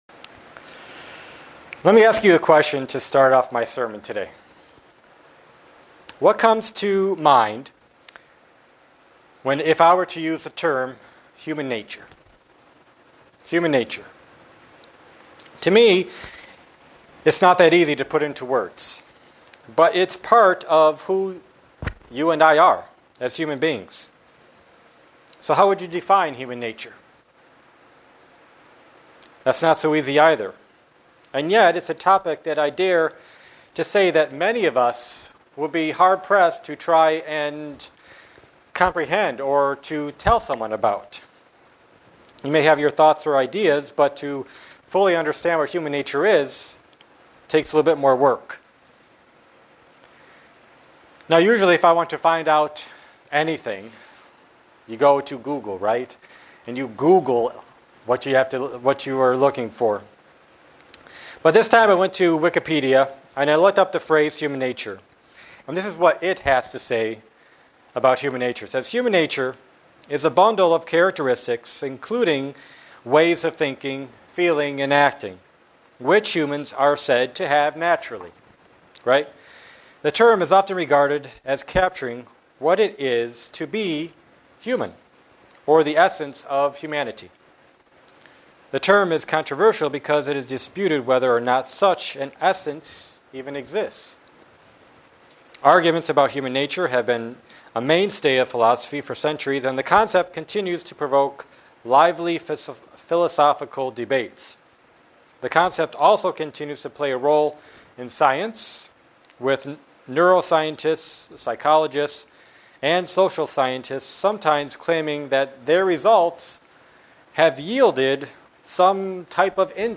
Print Using the term carnal mind found in the Bible to explain human nature. sermon Studying the bible?
Given in Buffalo, NY